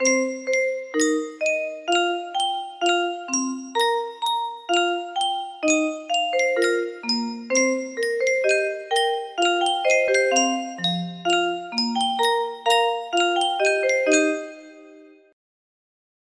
Yunsheng Music Box - Unknown Tune Y706
Full range 60